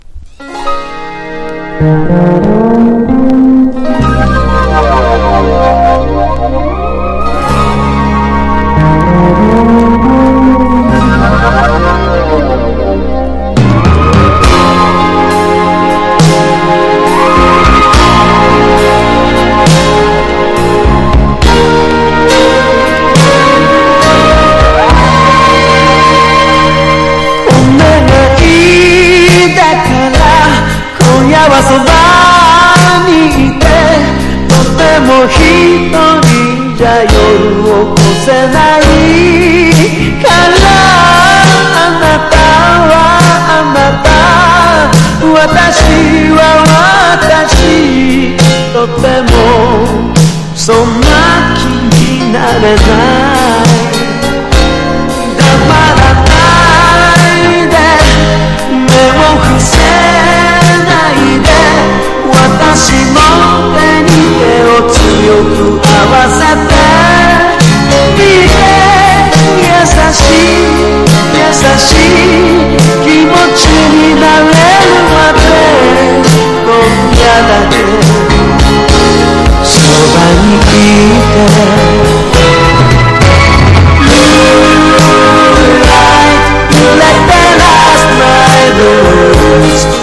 # POP# 和モノ